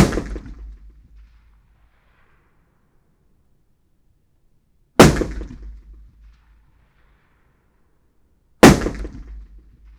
01_gunshot/shot556_165_ch01_180718_164328_80_.wav · UrbanSounds/UrbanSoundsNew at 76bfaa6c9dcc58084f9109a20dd5a56c091ddcfb
Environmental
Streetsounds
Noisepollution